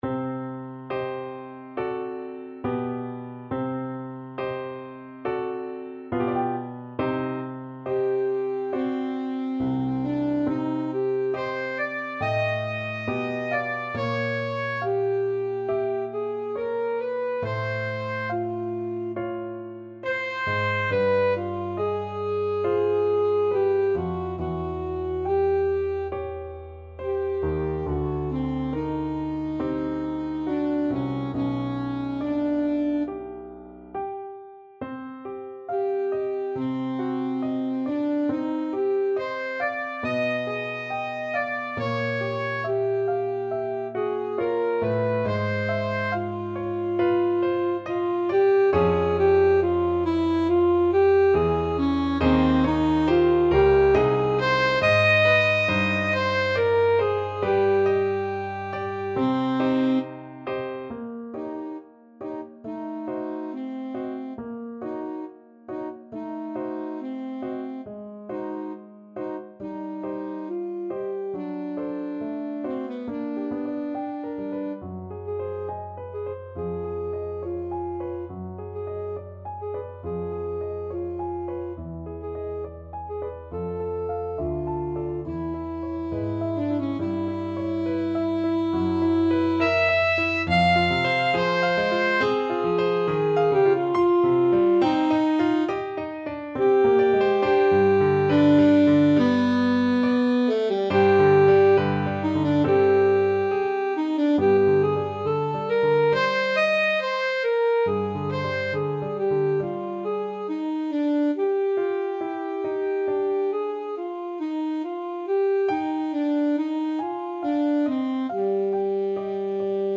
Classical Nielsen, Carl Fantasy Pieces, Op.2 No.1 Romanze Alto Saxophone version
Alto Saxophone
4/4 (View more 4/4 Music)
~ = 69 Andante con duolo
C minor (Sounding Pitch) A minor (Alto Saxophone in Eb) (View more C minor Music for Saxophone )
G4-F6
Classical (View more Classical Saxophone Music)